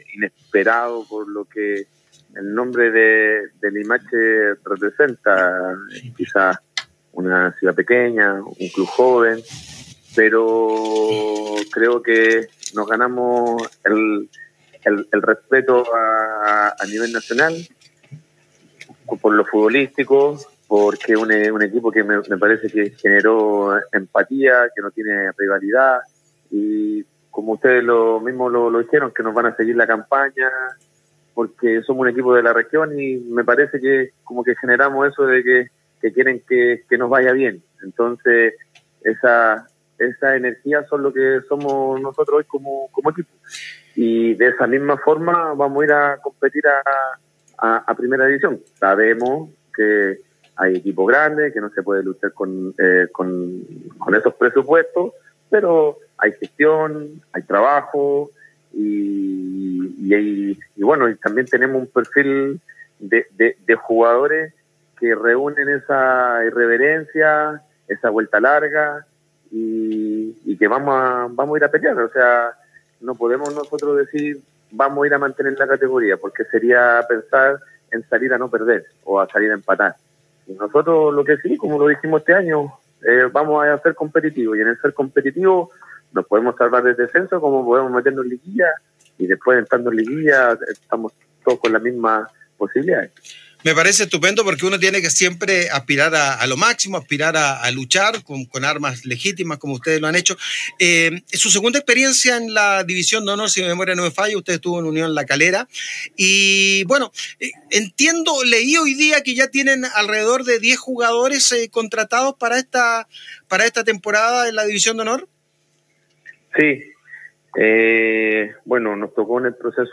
En conversación con Golazo UCV Radio